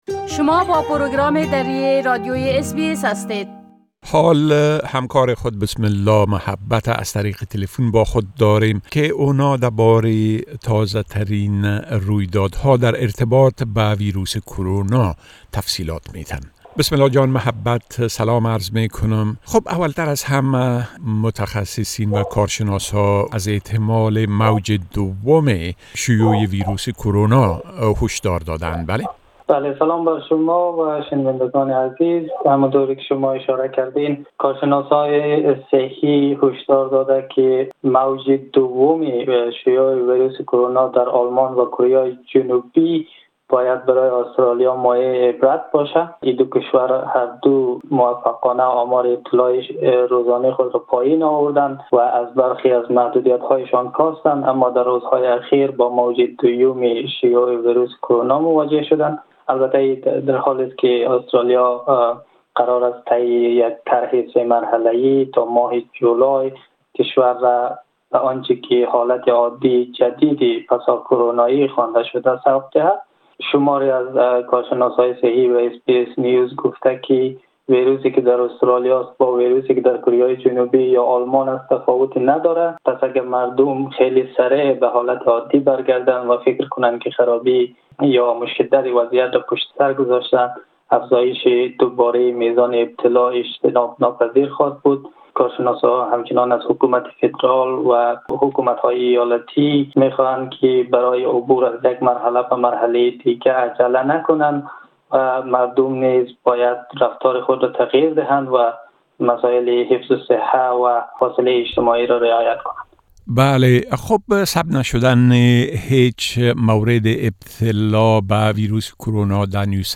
در این گفت‌‌وگو: